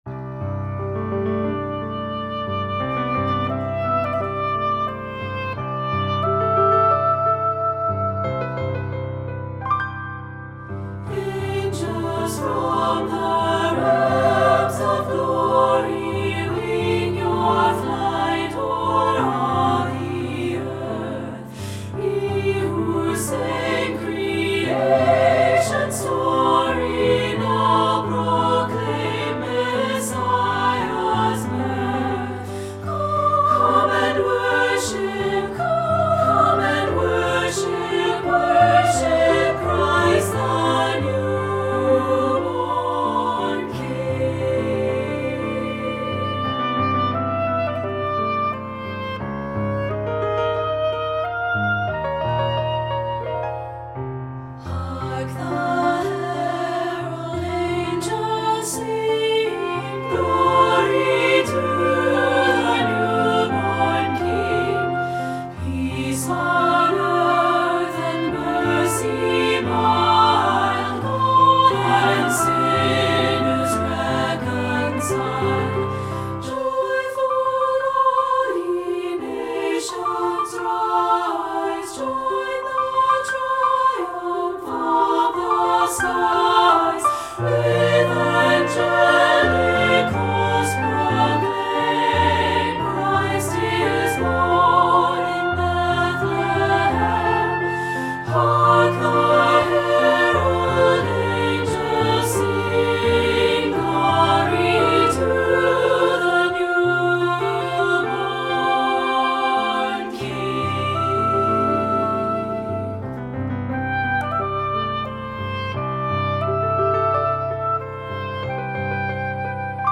Voicing: SSA and Piano